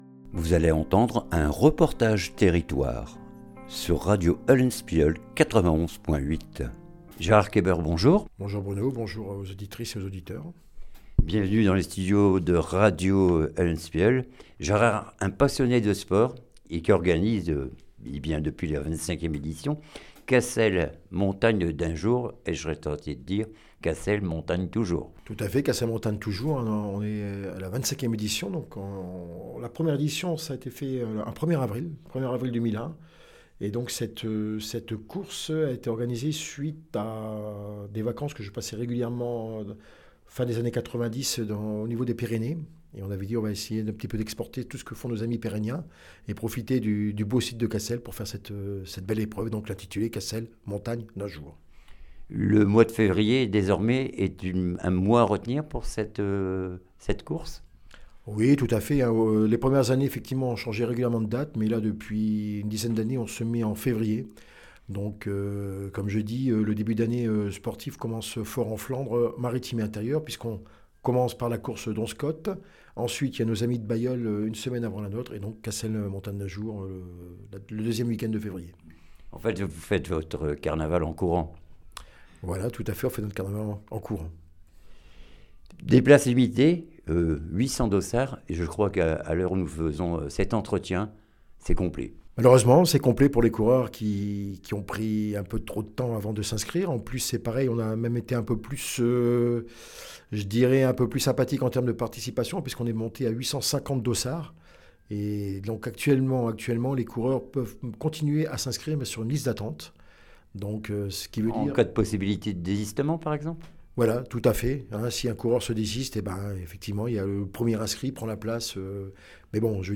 REPORTAGE TERRITOIRE CASSEL MONTAGNE D UN JOUR 8 FEV 2026